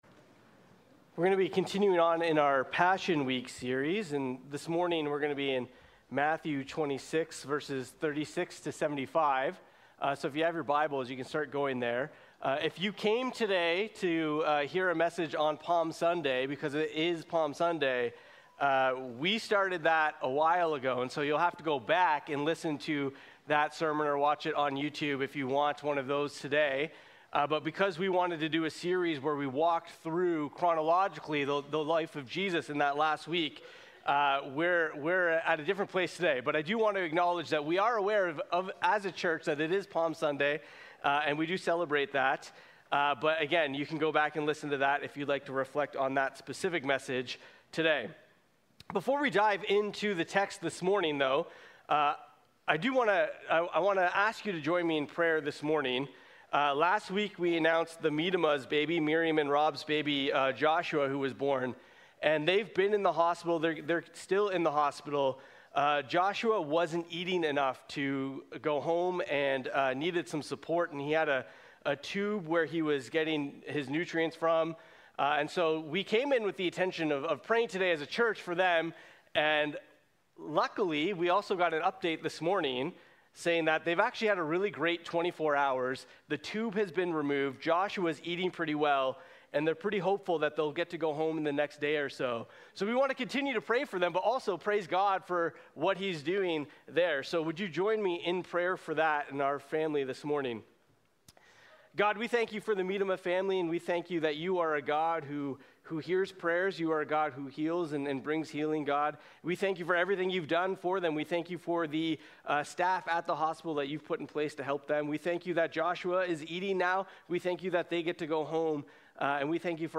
Sermons | James North Baptist Church